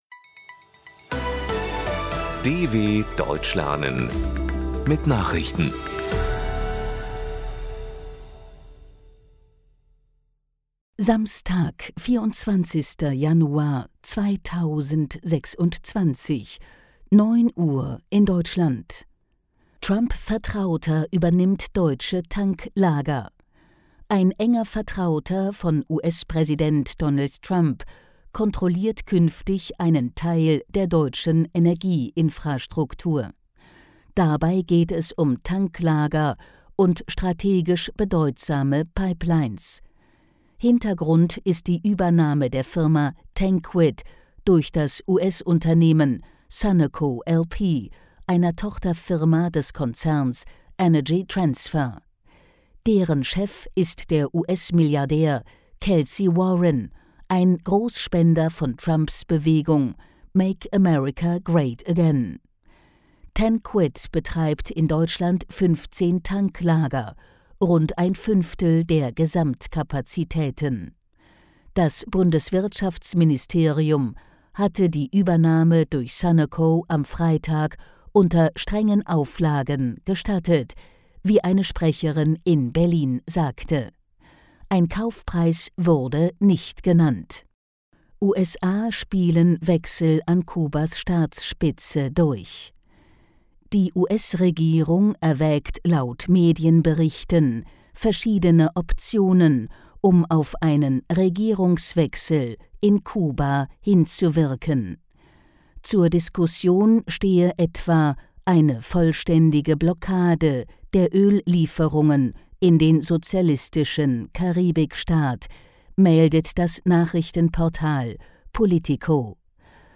24.01.2026 – Langsam Gesprochene Nachrichten
Trainiere dein Hörverstehen mit den Nachrichten der DW von Freitag – als Text und als verständlich gesprochene Audio-Datei.